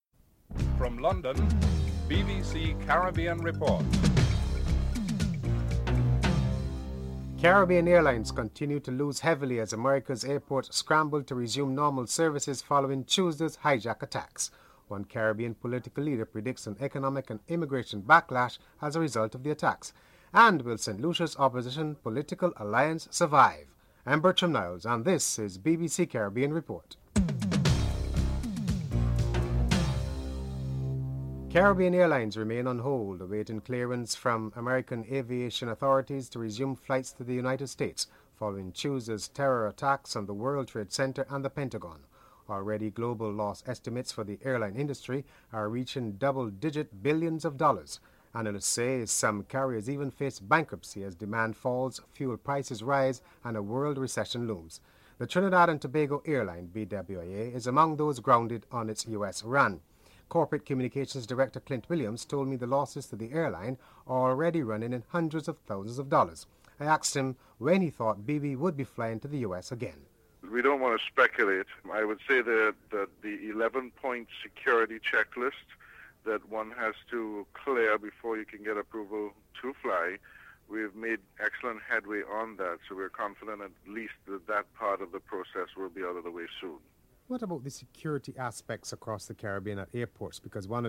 1. Headlines (00:00-00:31)